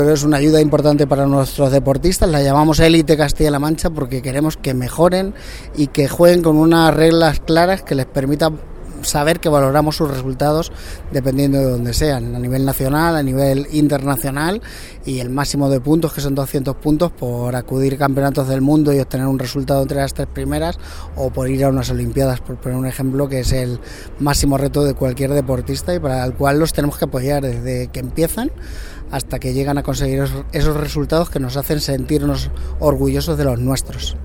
El director general de Juventud y Deportes habla de las subvenciones del Gobierno regional Élite Castilla-La Mancha.